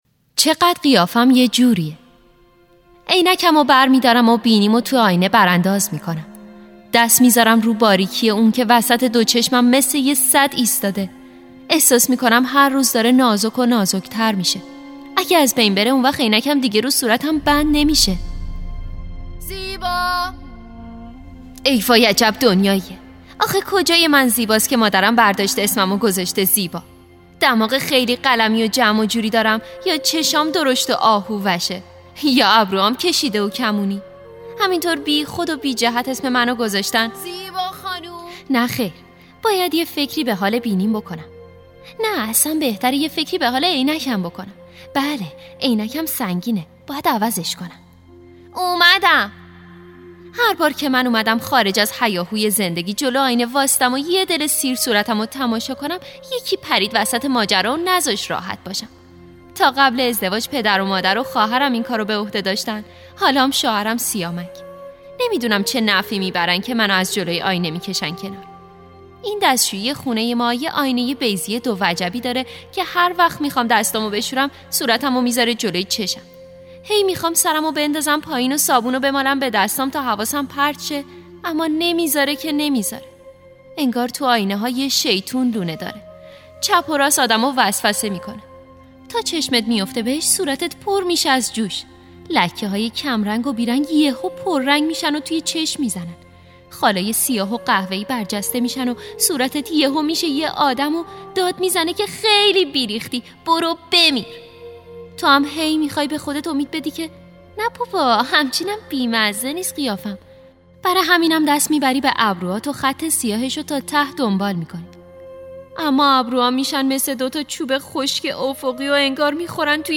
کتاب صوتی یه الف بچه / داستان‌های نماز